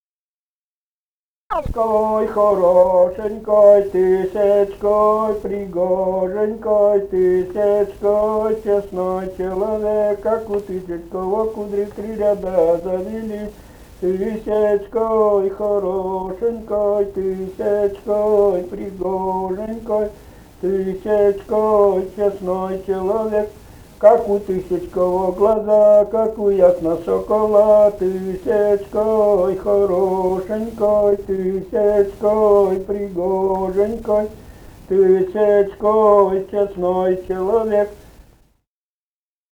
«Тысяцкой хорошенькой» (свадебная).